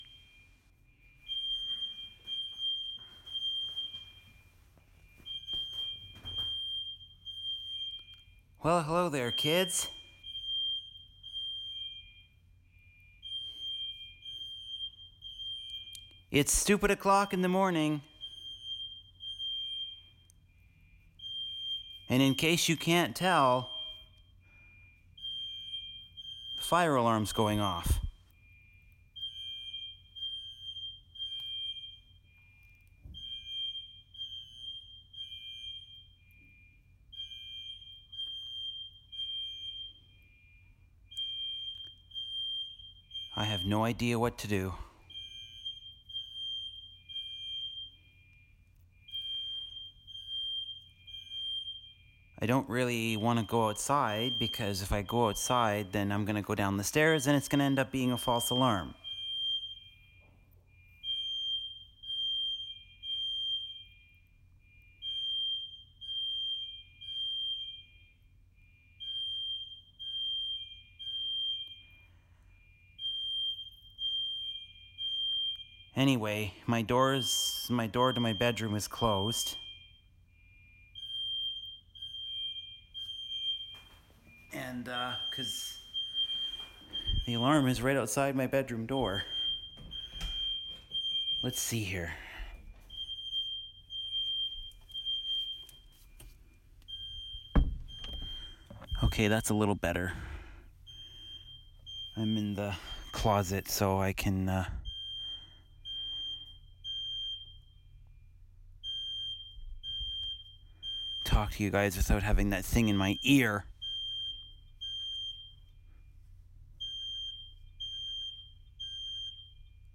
Good Lord, I hope this does not become a regular occurrence at my apartment building.